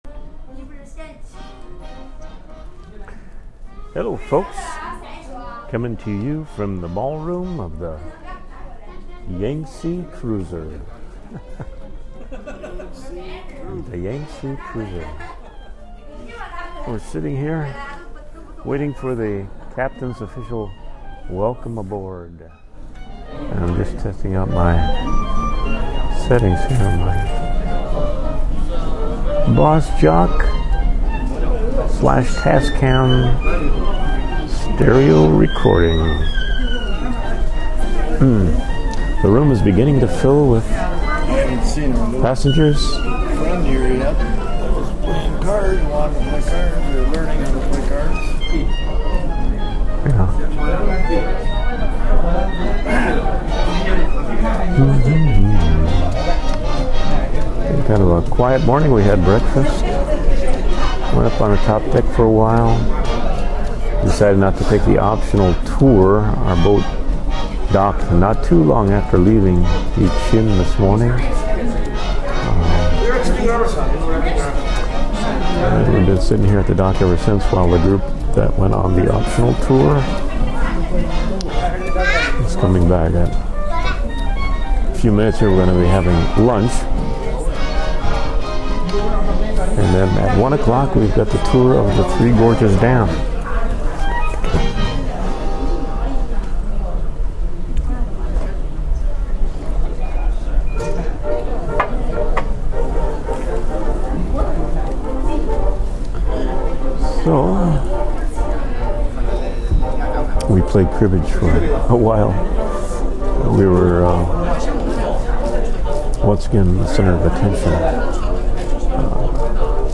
Captain's Welcome Speech